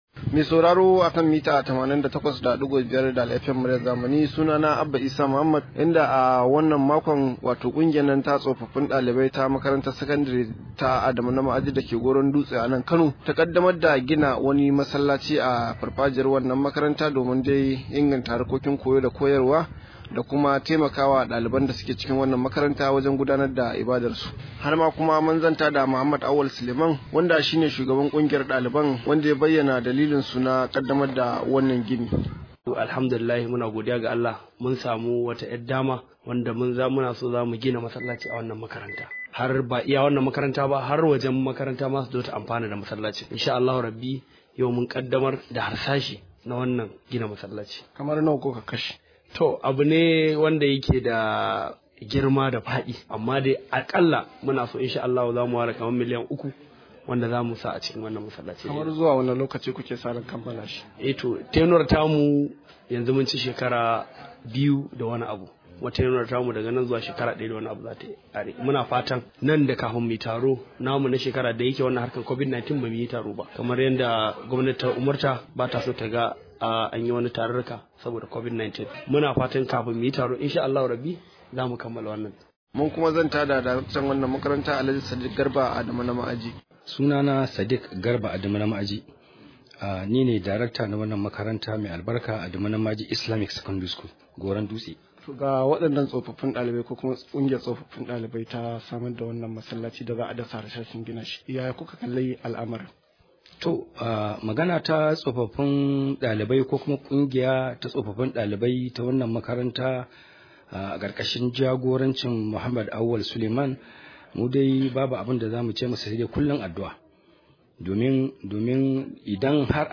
Rahoto: Akwai bukatar tsofaffin dalibai su rinka tallafawa makarantun su – Shugaban makaranta